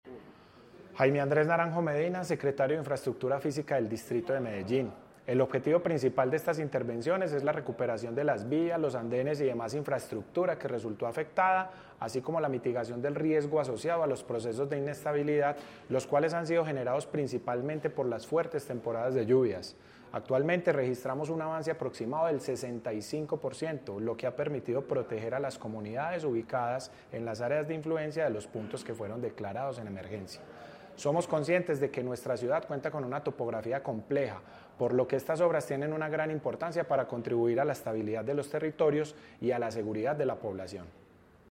Declaraciones secretario de Infraestructura Física, Jaime Andrés Naranjo Medina La Alcaldía de Medellín continúa avanzando en la atención de los puntos declarados en emergencia durante la primera temporada de lluvias de 2025.
Declaraciones-secretario-de-Infraestructura-Fisica-Jaime-Andres-Naranjo-Medina.mp3